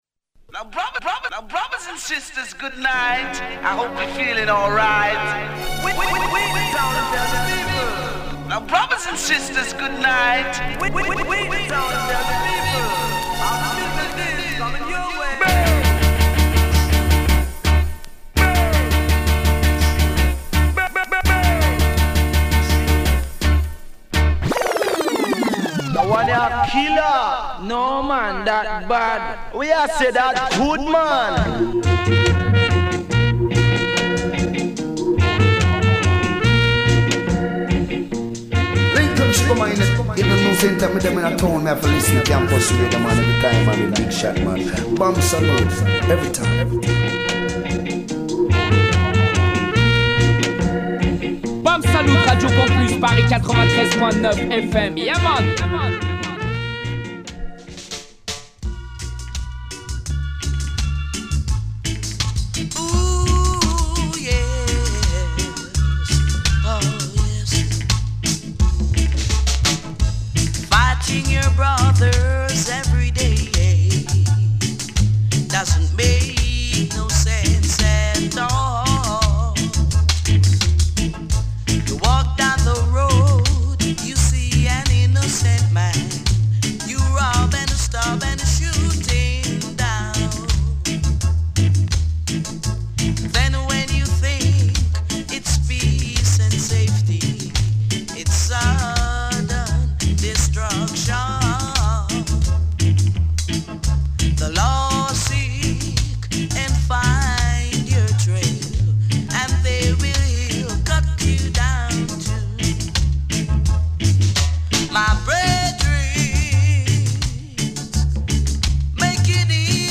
Musicale